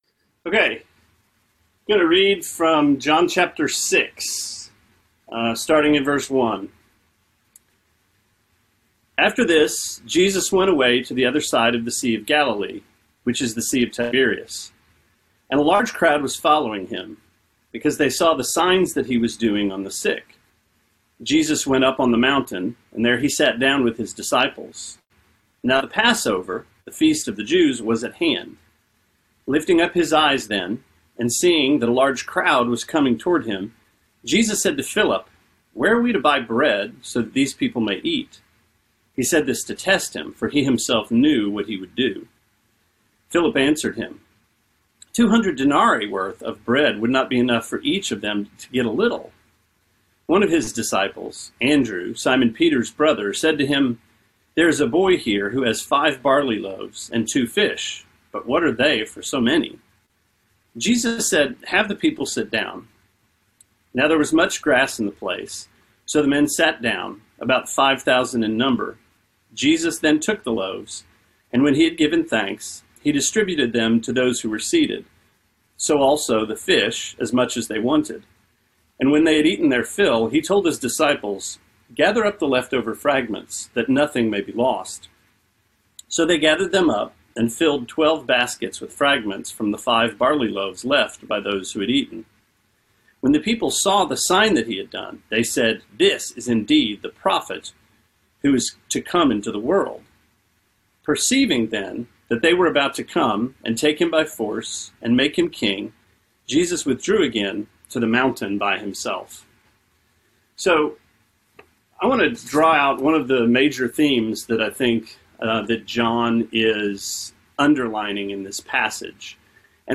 Sermonette 5/1: John 6:1-15: New Exodus